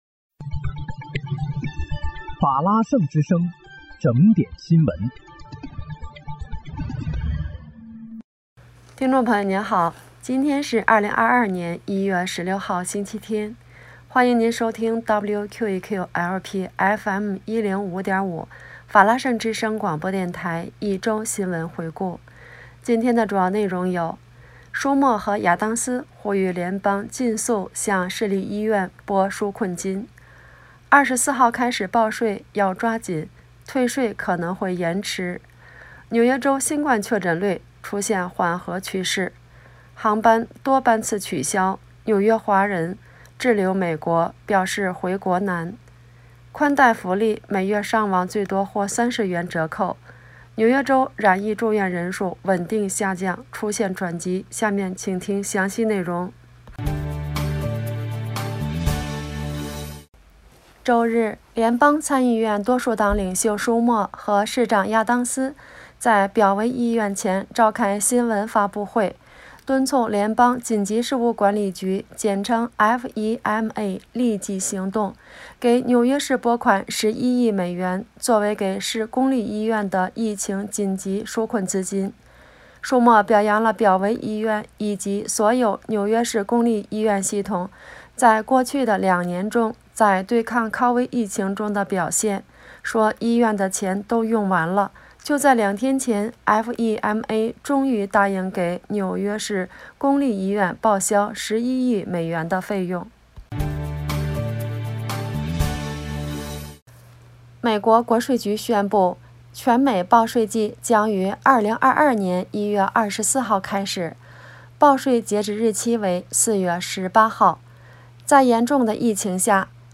1月16日(星期日）一周新闻回顾